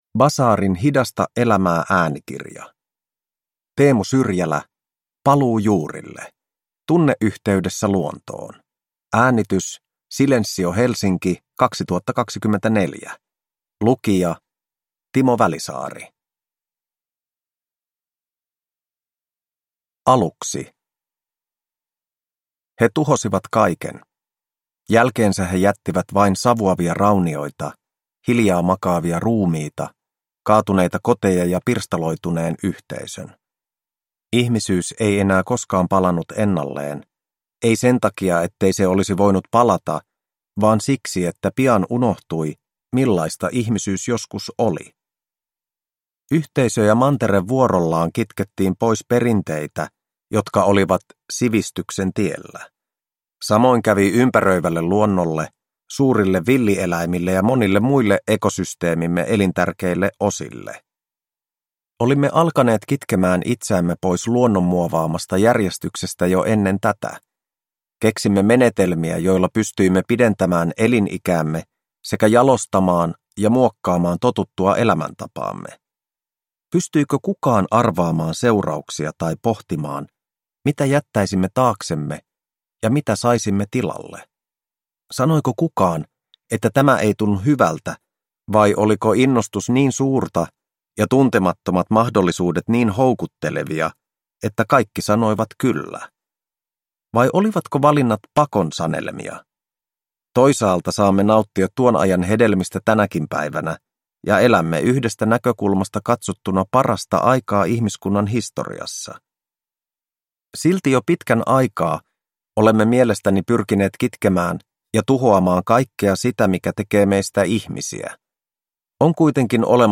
Paluu juurille – Ljudbok